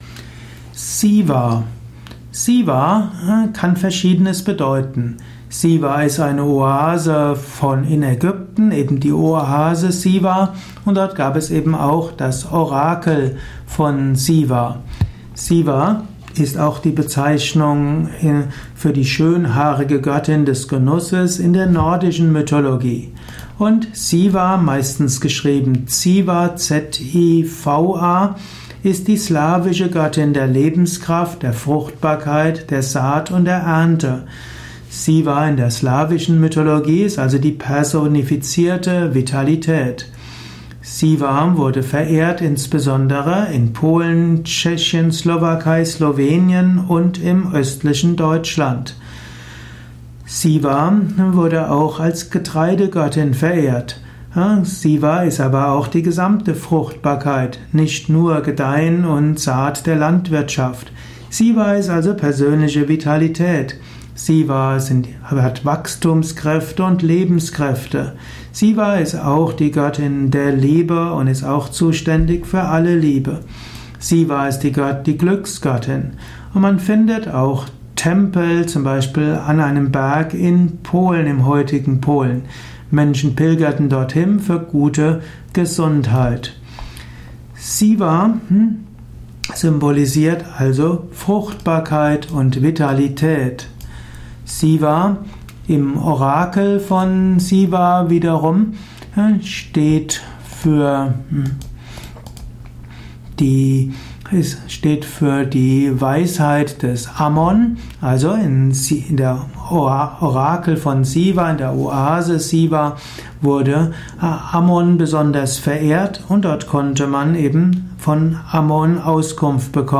Dies ist die Tonspur eines Videos, zu finden im Yoga Wiki.